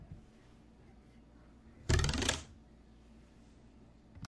描述：我把一把塑料尺子一半在桌子边缘，一半在桌子边缘，像跳水板一样翻动，在翻动的过程中把尺子向桌子方向移动。